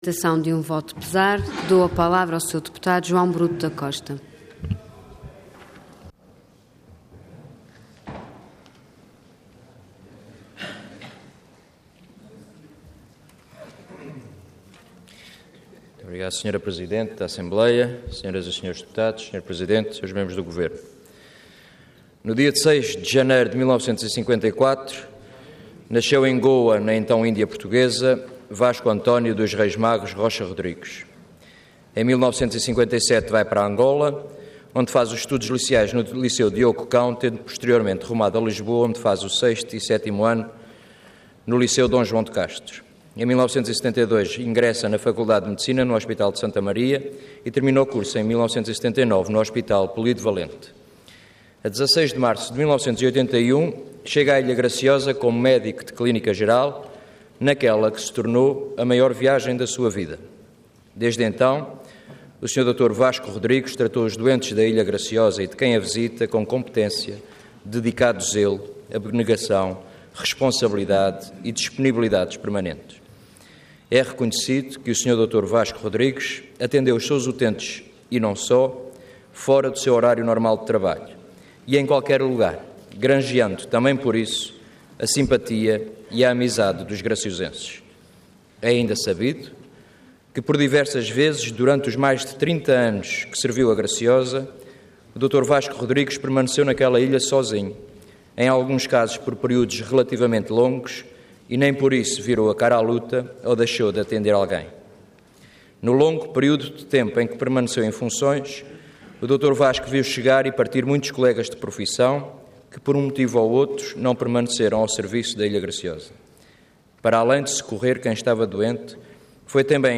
Intervenção Voto de Pesar Orador João Bruto da Costa Cargo Deputado Entidade PSD